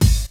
Wu-RZA-Kick 15.WAV